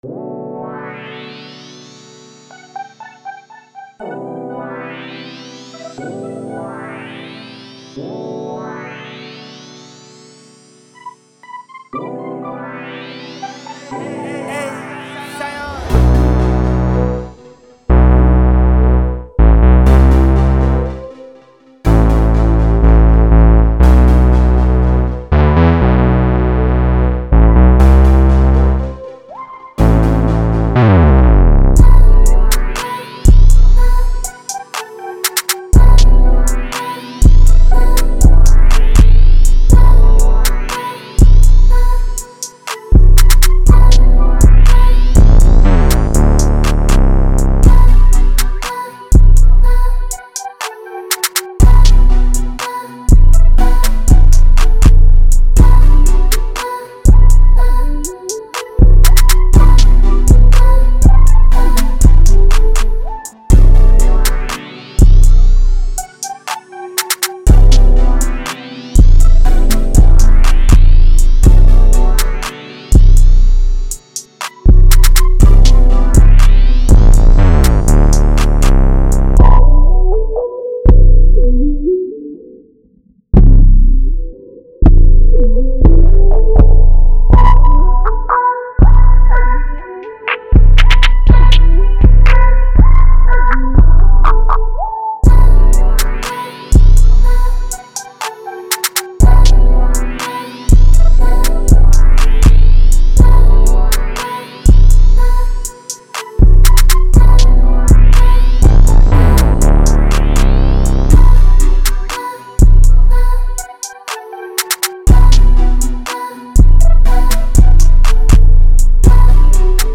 121 G Major